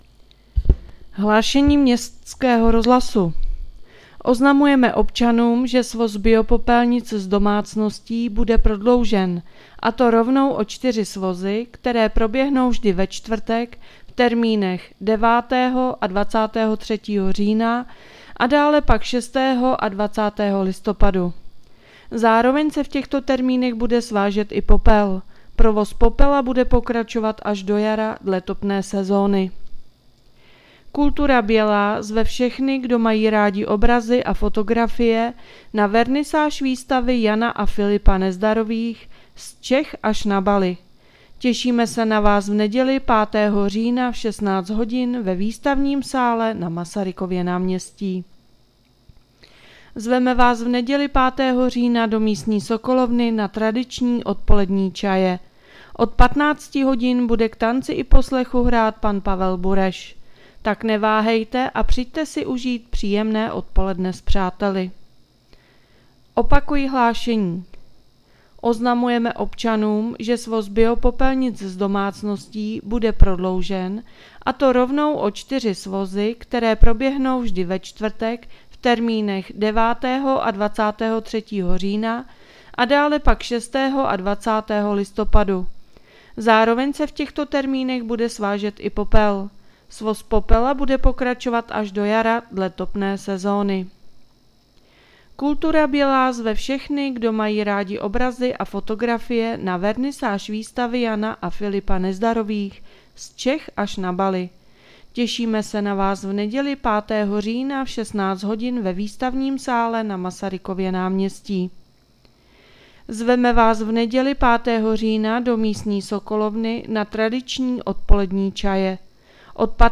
Hlášení městského rozhlasu 3.10.2025